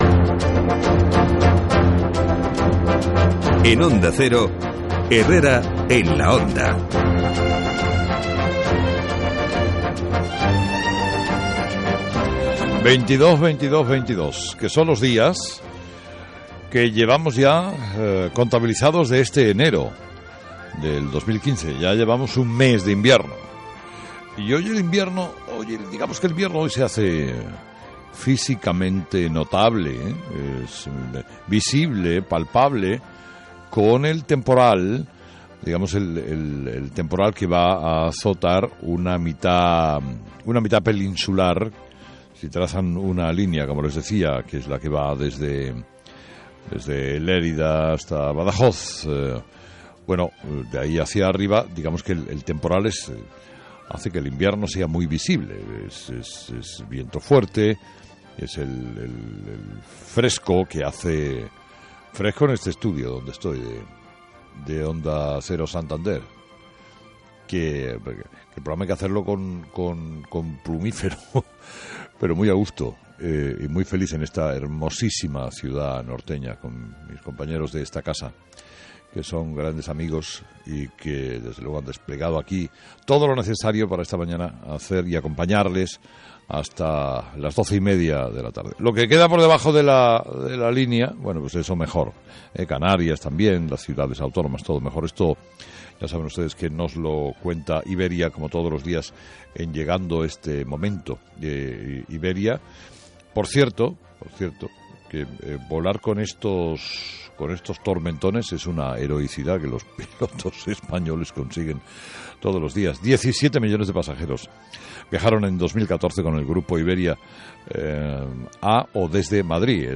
Carlos Herrera repasa en su editorial los temas que serán noticia en este jueves pero se centra en lo que llama la "carnicería de la izquierda".